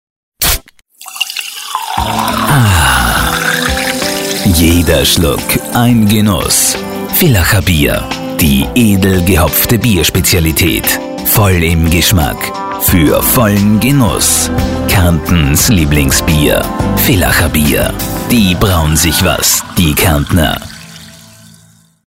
Freundliche, tiefe Männerstimme in den besten Jahren, schnell und flexibel - vom Telefonspot bis zur Stationvoice.
deutsch
Sprechprobe: Werbung (Muttersprache):